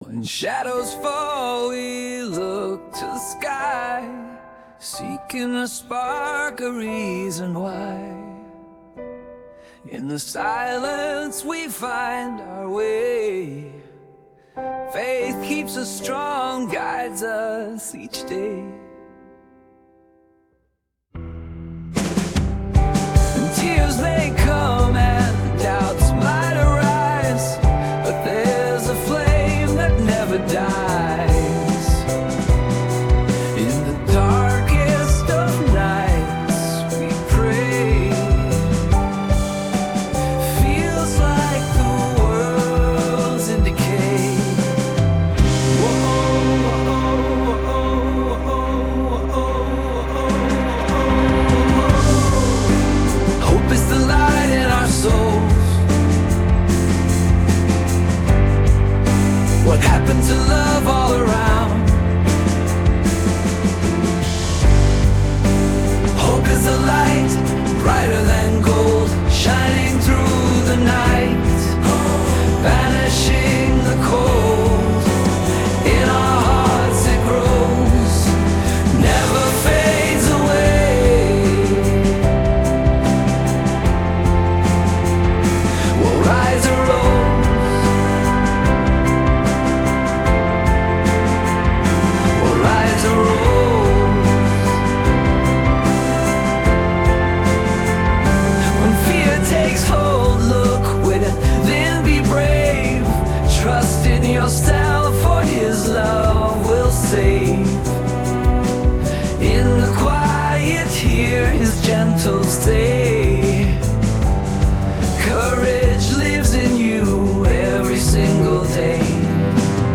Mit Hilfe eines KI-Programms entstand der Song: “Light in our souls”.
Daher mussten wir getrennt nochmal abstimmen: 1. über den Musikstil (Ergebnis: 72 von 224 Personen haben für “Pop-Rock wie Coldplay” gestimmt). 2. über die Inhalte der Lyrics (Ergebnis: 130 von 374 Personen haben für “Hoffnung” gestimmt)